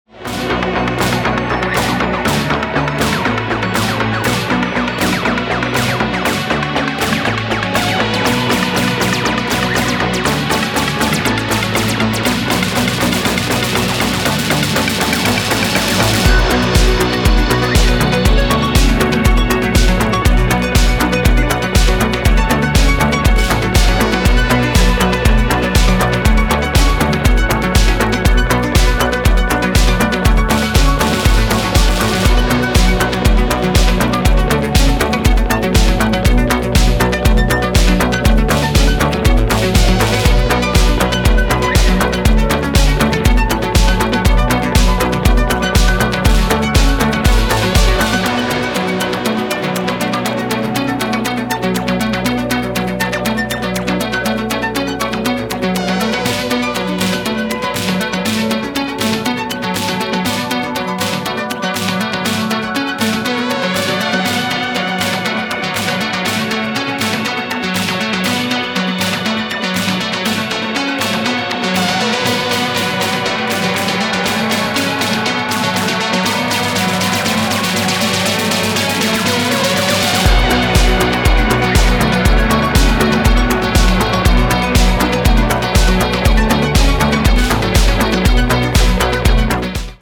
Electronic disco and house in its broadest sense